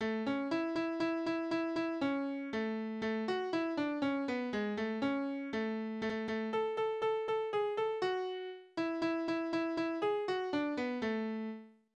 Couplets:
Tonart: A-Dur
Taktart: 4/8
Tonumfang: große None
Besetzung: vokal